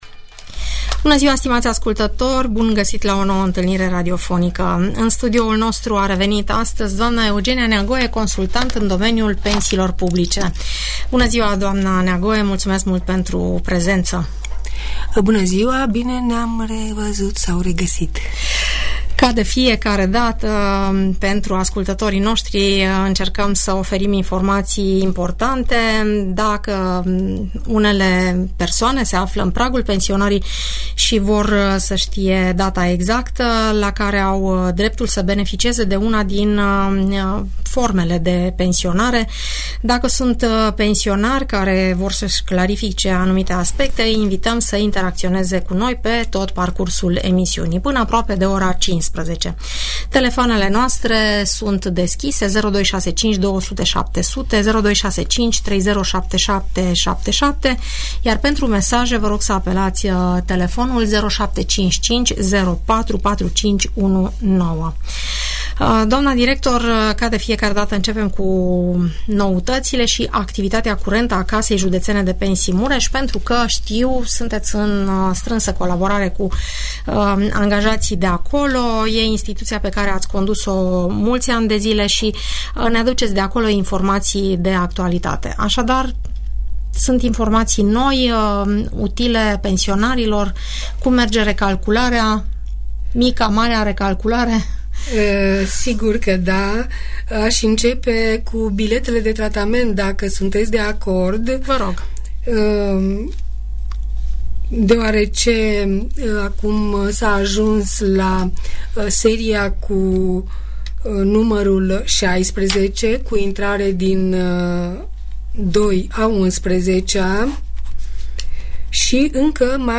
» Informații utile pentru pensionari Informații utile pentru pensionari Întrebări și răspunsuri despre toate tipurile de pensii, în emisiunea "Părerea ta" de la Radio Tg Mureș. în dialog cu ascultătorii.